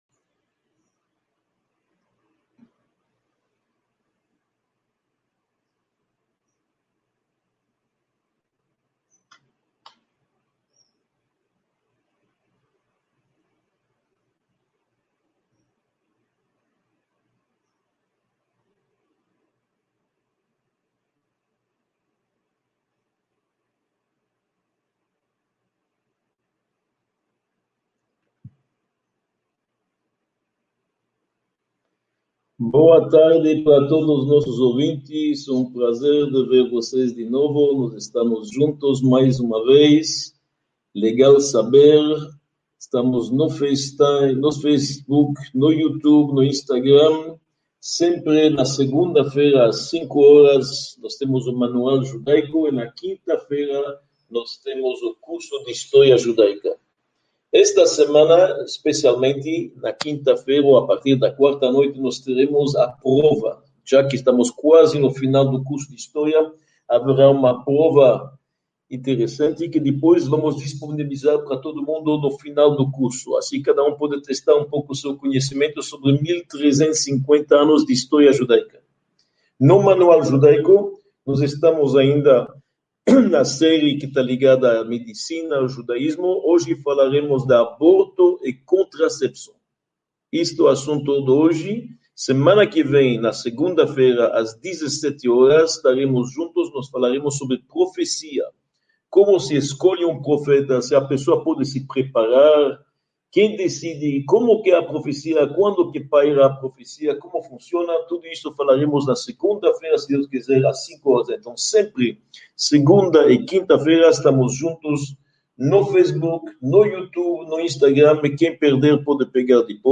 21 – Aborto e Contracepção | Módulo I – Aula 21 | Manual Judaico